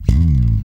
-JP THUMBSLD.wav